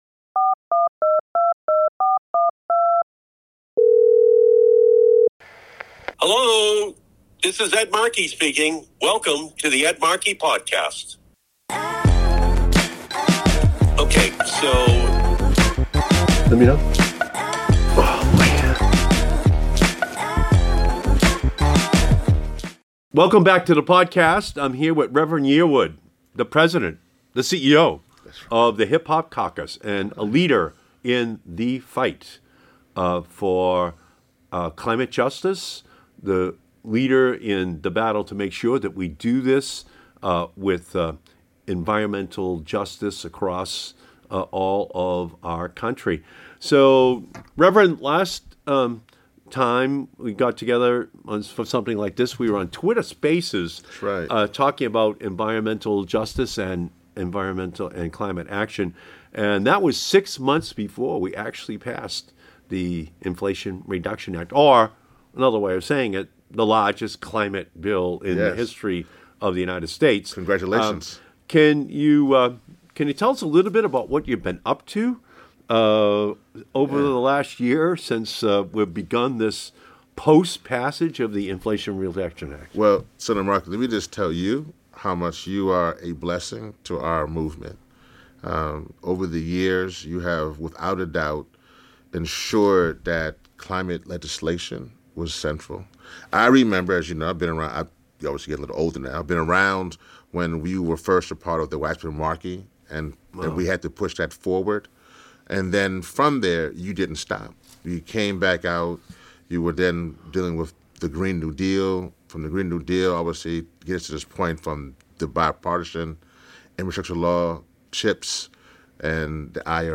Join Senator Markey and Reverend Yearwood the President and CEO of the Hip Hop Caucus as they discuss the fight for environmental justice and climate action.